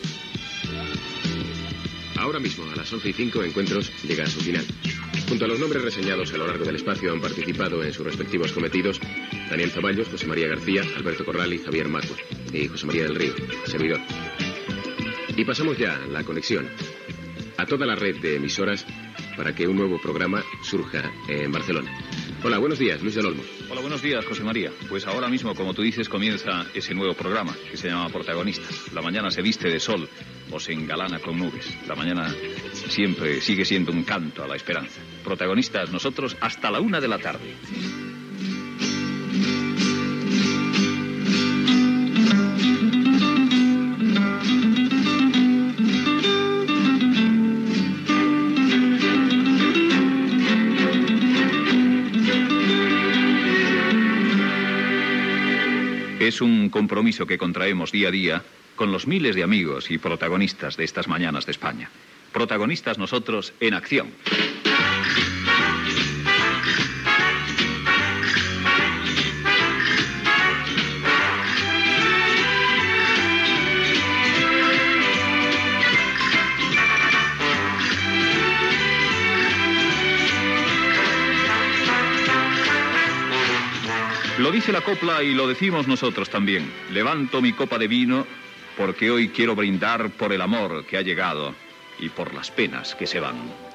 Primeres paraules, sintonia de RNE i del programa, frase popular
Entreteniment